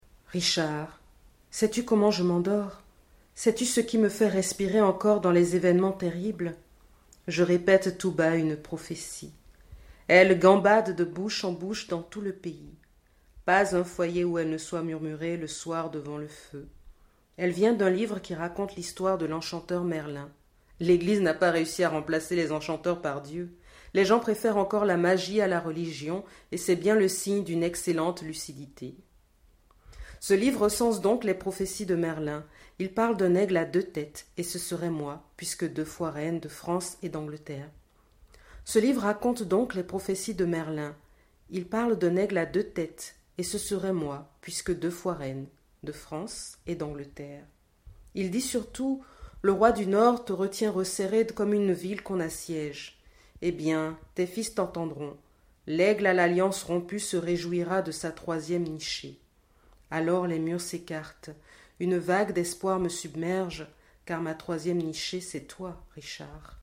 Plutôt que de les reproduire, je vous les lis :